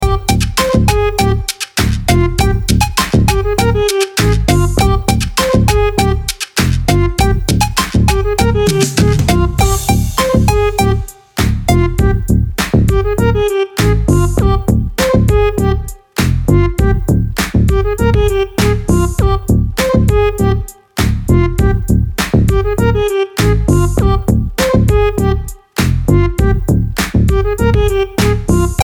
latin neo-soul tracks